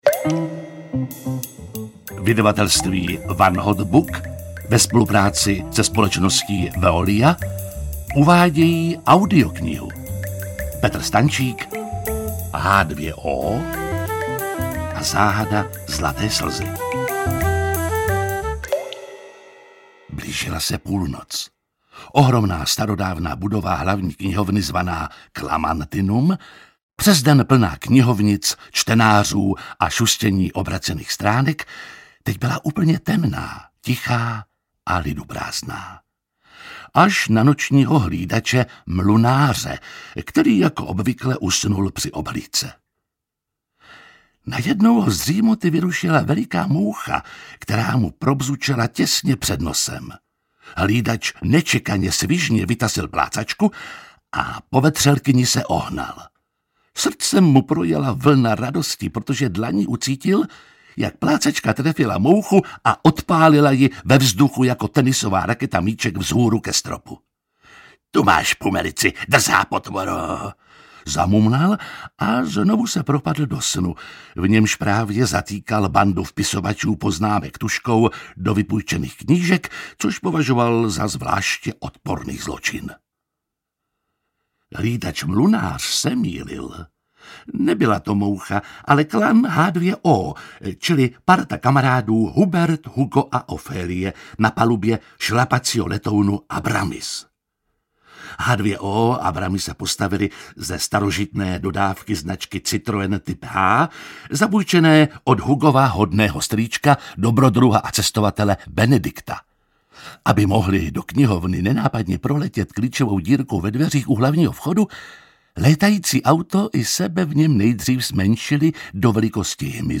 Audio knihaH2O a záhada Zlaté slzy
Ukázka z knihy
• InterpretJiří Lábus
h2o-a-zahada-zlate-slzy-audiokniha